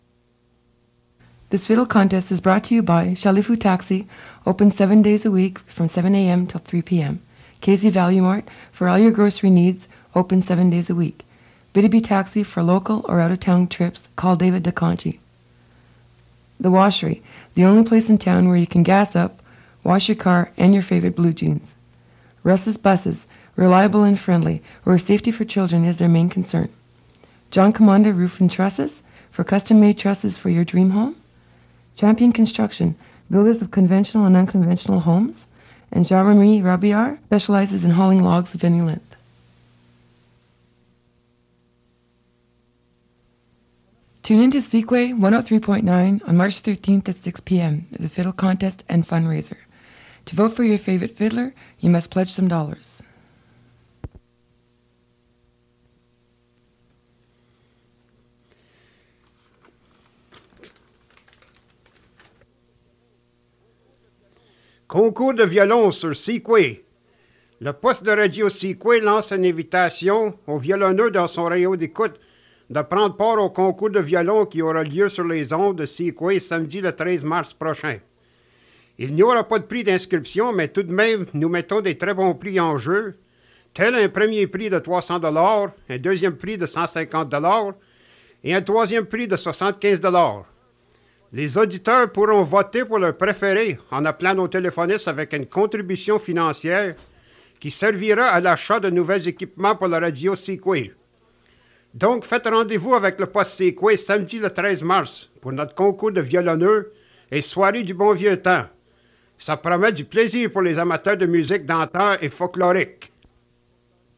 Fait partie de Fiddle contest and folk evening